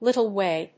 little+way.mp3